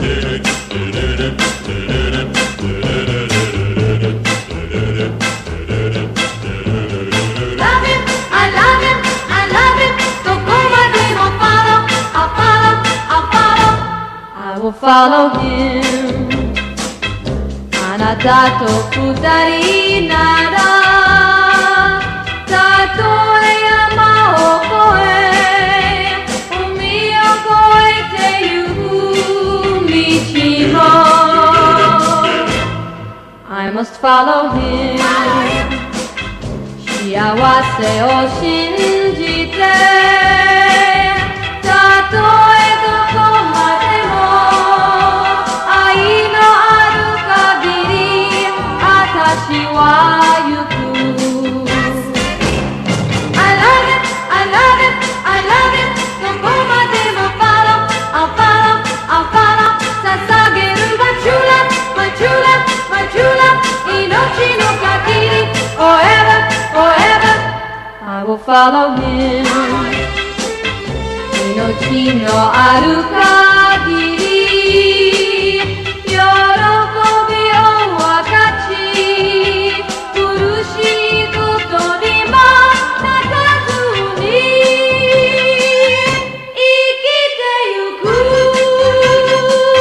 ロッキン・ディスコ/ハウスな仕上がりの
アシッドな